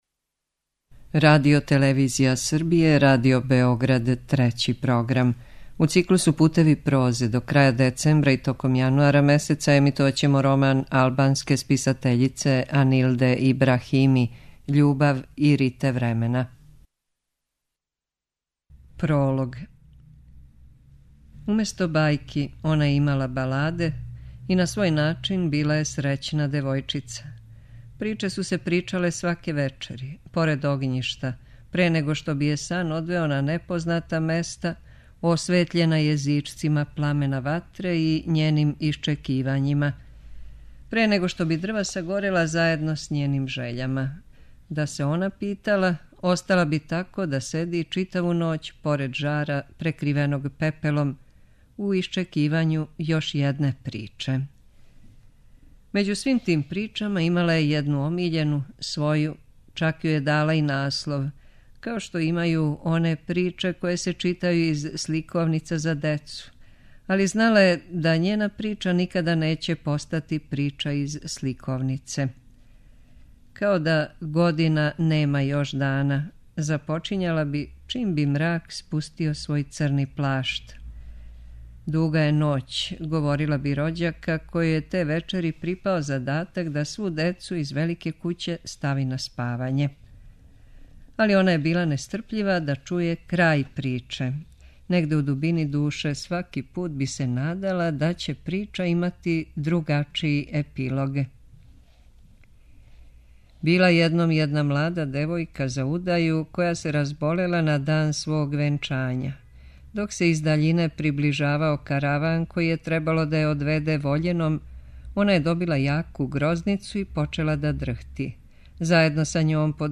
преузми : 13.01 MB Књига за слушање Autor: Трећи програм Циклус „Књига за слушање” на програму је сваког дана, од 23.45 сати.